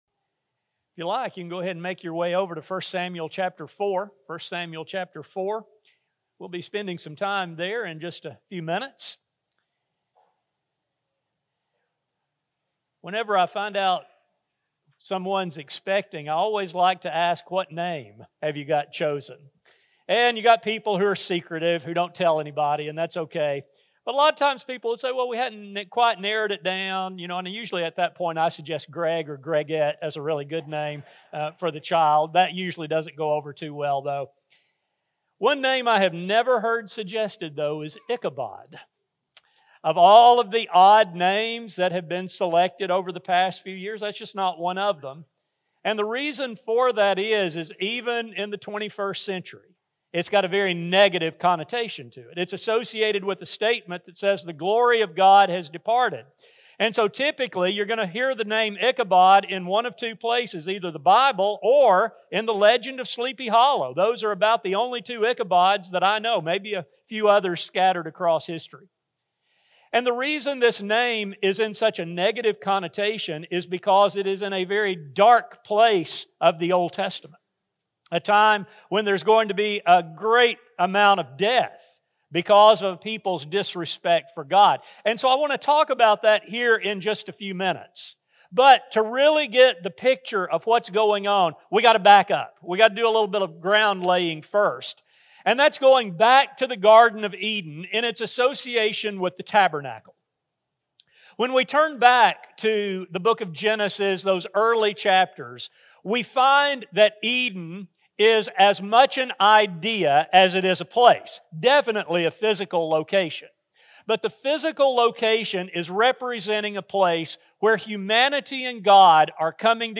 There are many lessons modern Christians can learn from these ancient Jews. A sermon recording